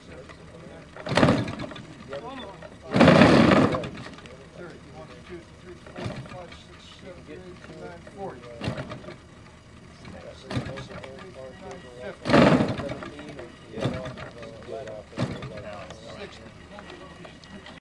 印度 " 渔船上的发动机空转和转速接近尾声时，有声音和飞机通过 印度
描述：渔船船上发动机空转和转动近端关闭声音和飞机通过India.flac
Tag: 船上 钓鱼 怠速 转速 印度 发动机